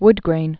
(wdgrān)